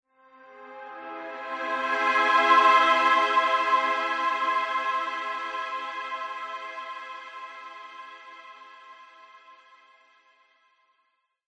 Звуки фэнтези
Фантазийный вздох, таинственный и воздушный, музыкально легкий